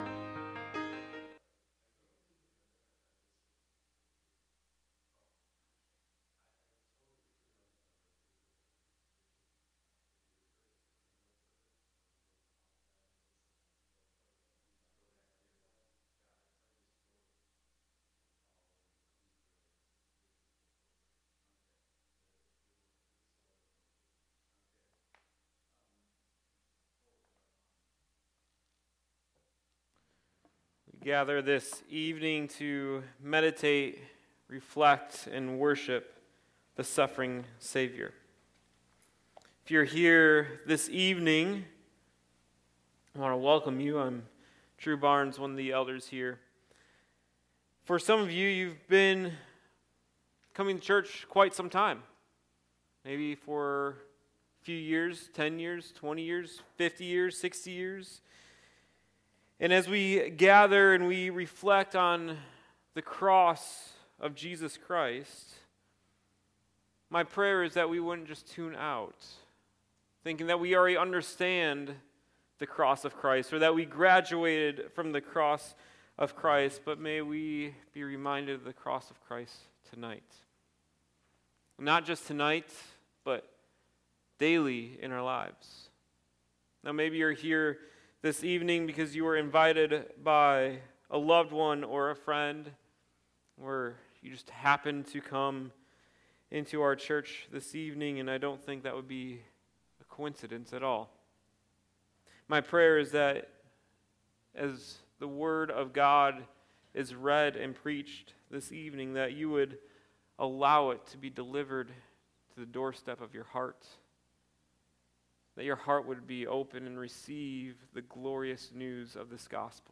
Sermons | Meadows Christian Fellowship